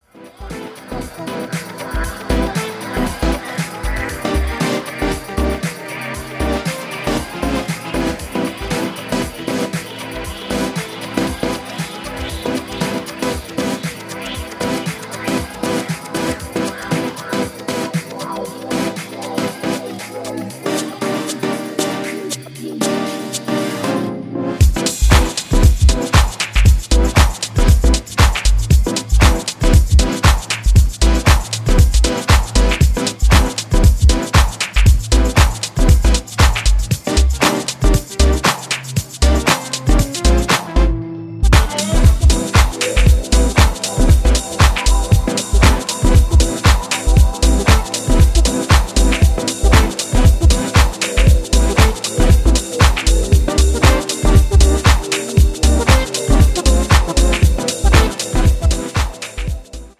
さりげないサンプリング・センスも抜群です！